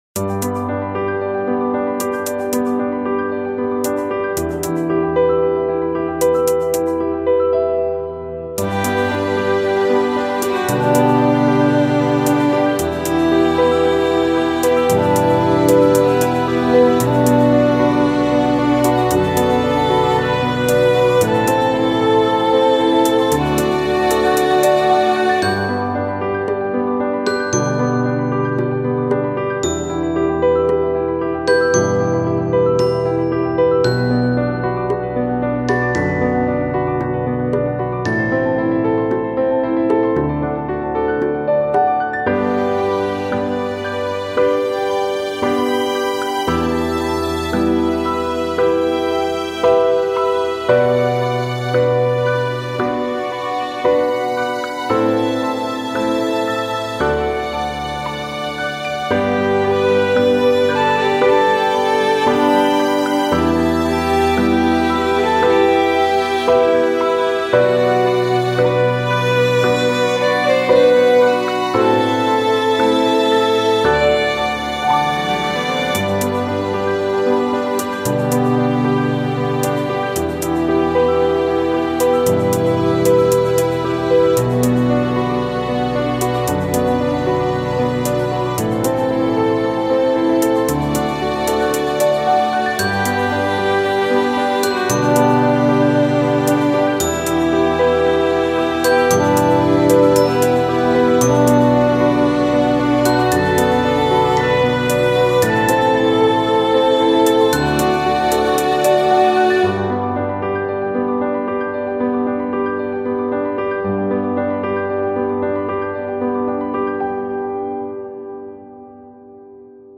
ピアノがメインの優しい雰囲気漂うBGMです。時々バイオリンがメインメロディーを奏でます。
あまりメロディーが主張しないので、企業VPやCM、映像作品等、BGMとして扱いやすくなっています。